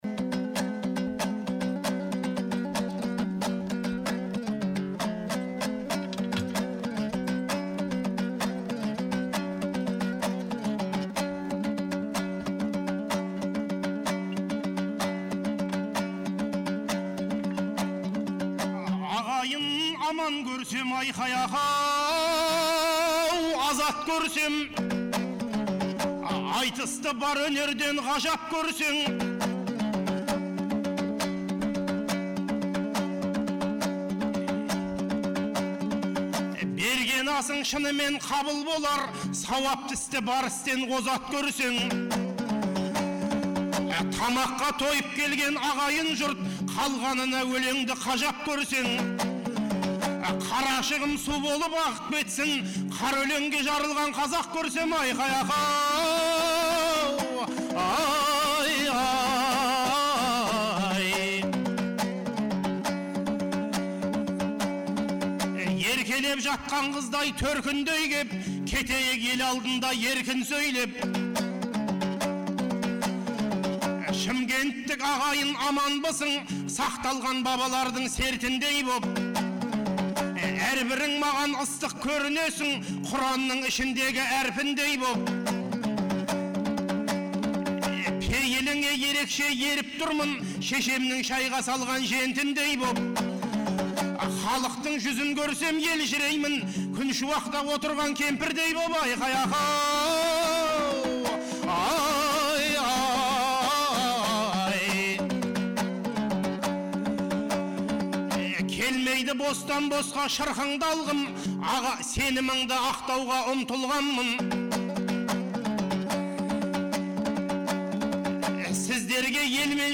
Наурыздың 15-16 күндері Шымкент қаласында екі күнге созылған республикалық «Наурыз» айтысы өткен болатын. 2004 жылдан бері тұрақты өтіп келе жатқан айтыс биылғы жылы Төле бидің 350 және Абылай ханның 300 жылдықтарына арналды.